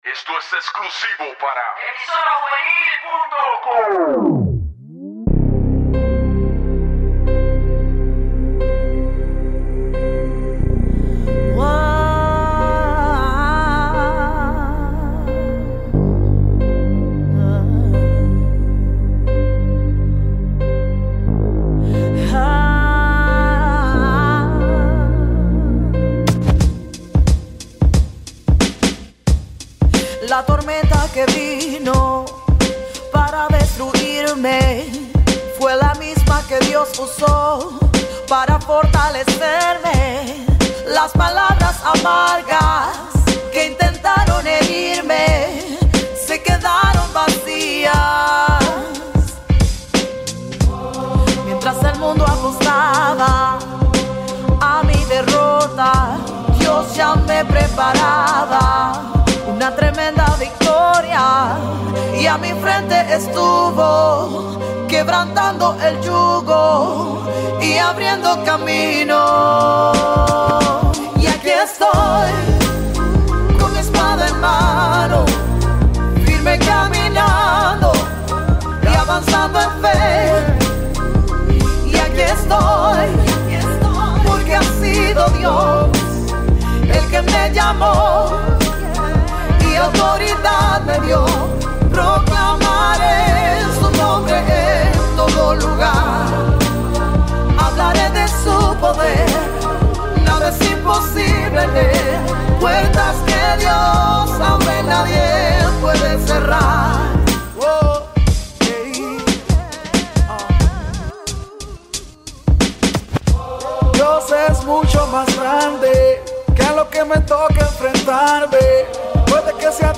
Musica Cristiana